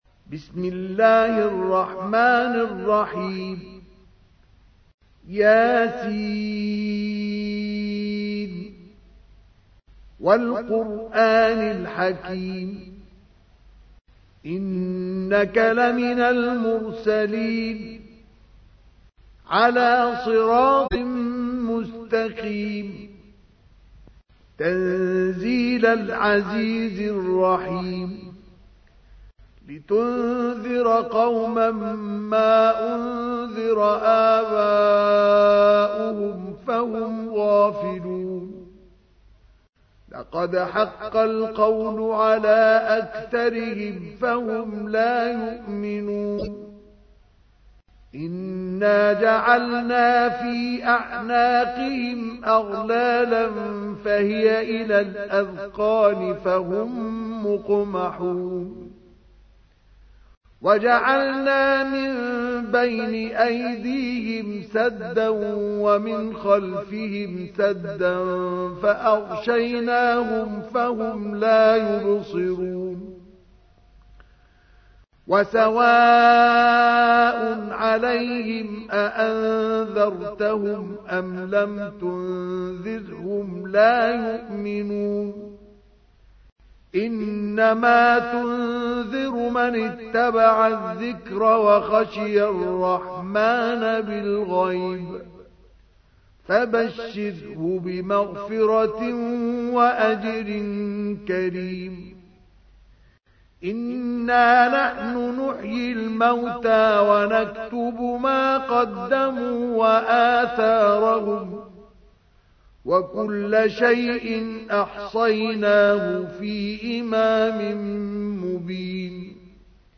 تحميل : 36. سورة يس / القارئ مصطفى اسماعيل / القرآن الكريم / موقع يا حسين